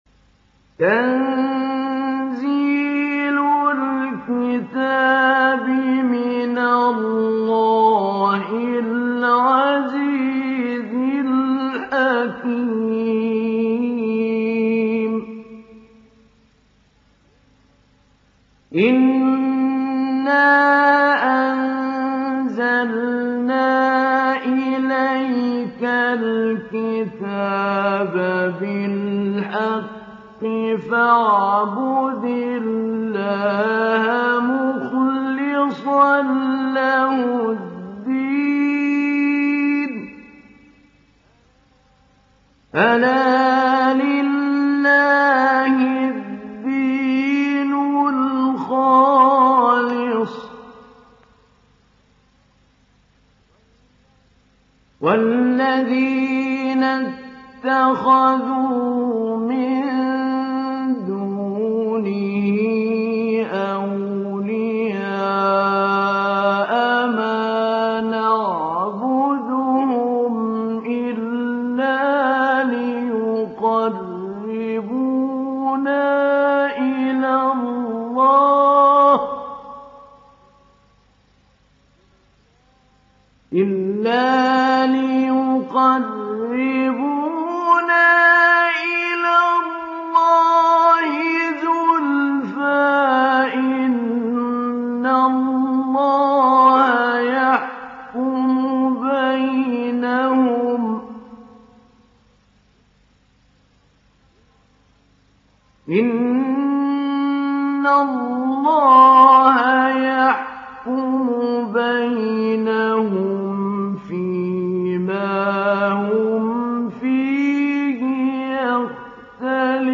ডাউনলোড সূরা আয-যুমার Mahmoud Ali Albanna Mujawwad